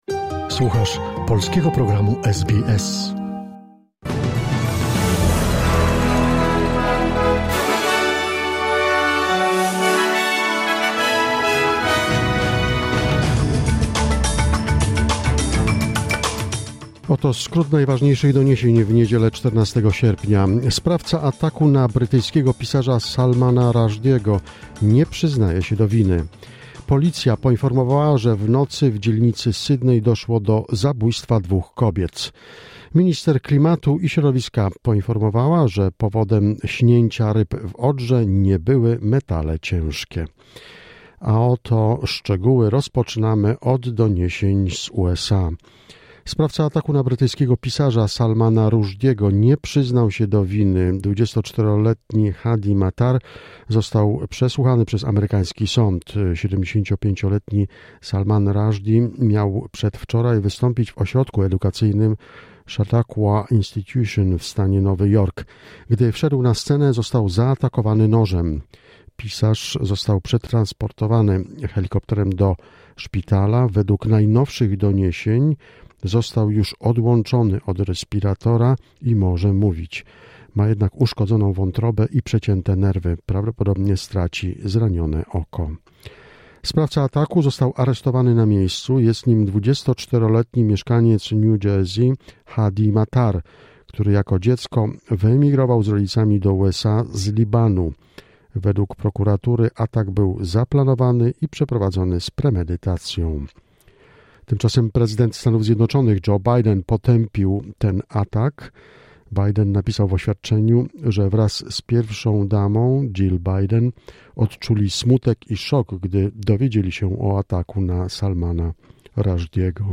SBS News in Polish, 14 August 2022